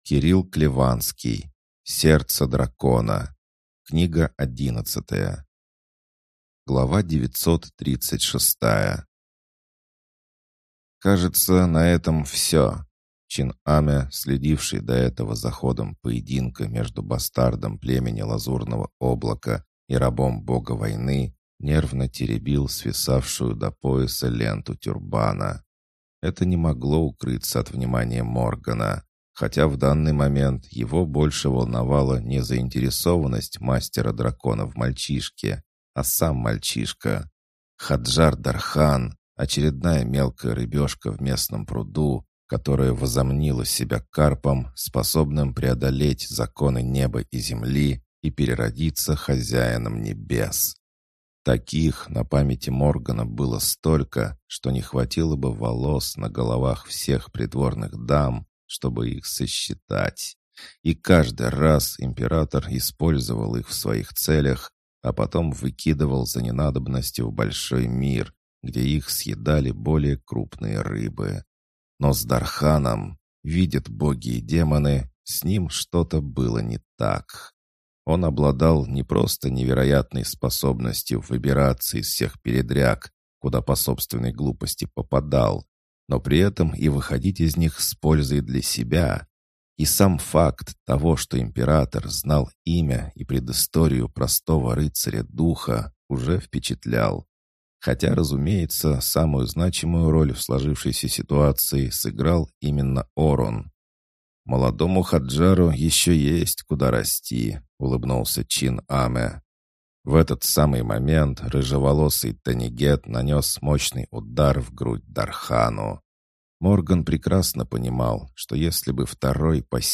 Аудиокнига Сердце Дракона. Книга 11 | Библиотека аудиокниг